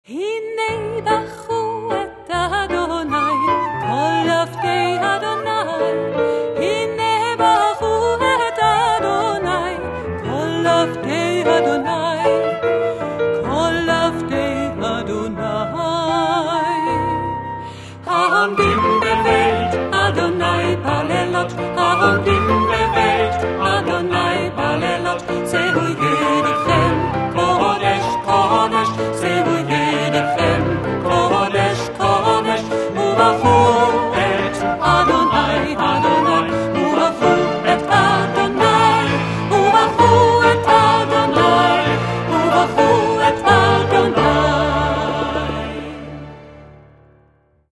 Querflöte, Altquerflöte